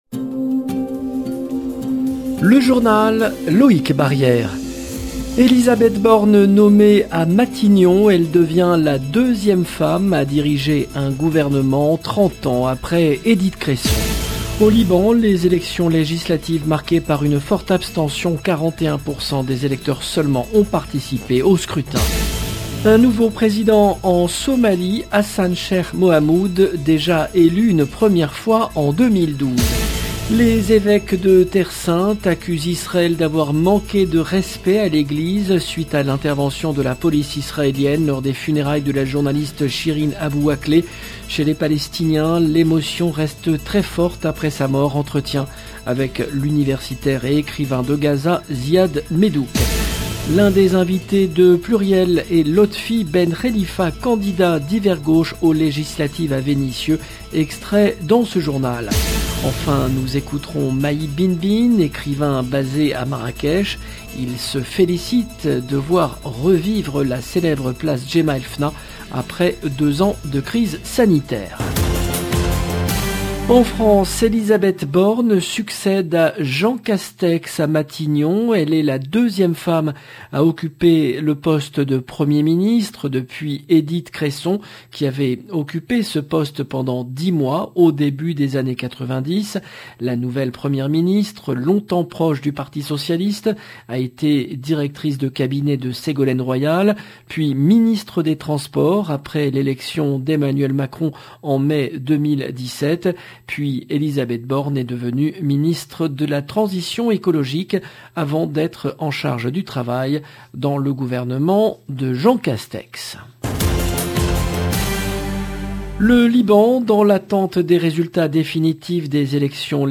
LE JOURNAL DU SOIR EN LANGUE FRANCAISE DU 16/05/22